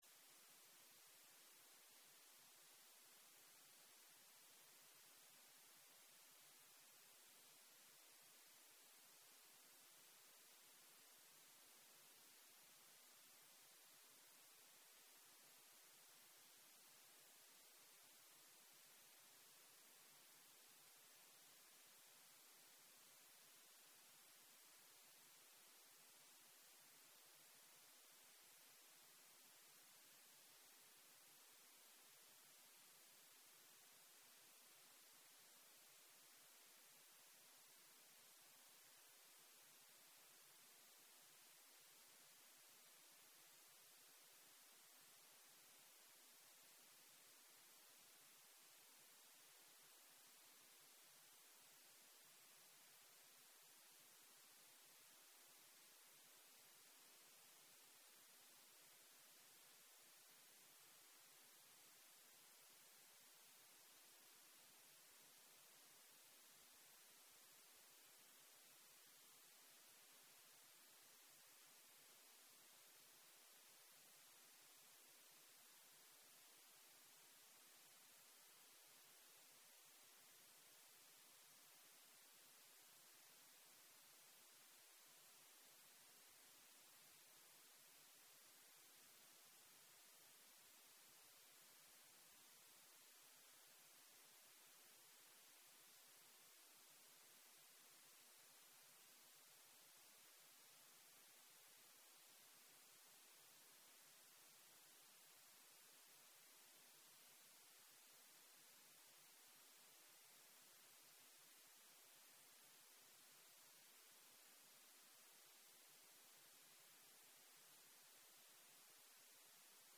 יום 3 - ערב - מדיטציה מונחית - הקלטה 7
סוג ההקלטה: מדיטציה מונחית